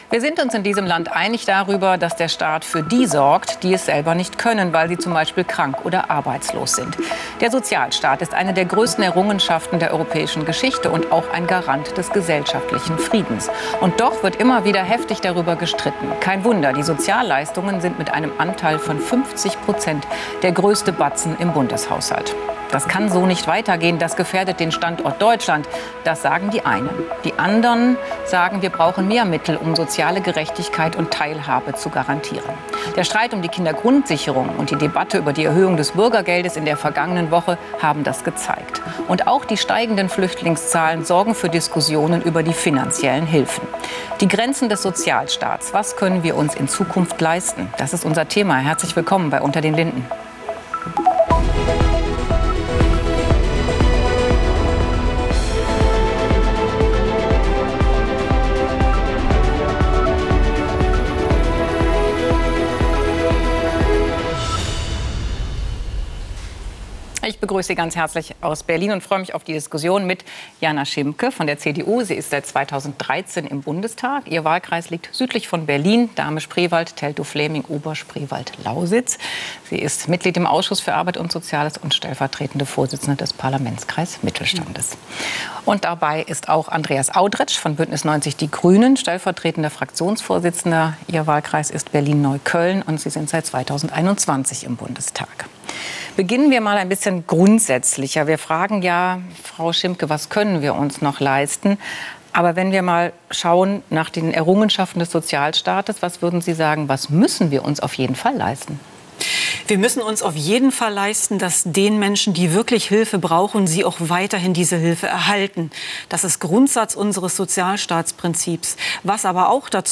„unter den linden“ ist das politische Streitgespräch bei phoenix.
Die Diskussionen sind kontrovers, aber immer sachlich und mit ausreichend Zeit für jedes Argument.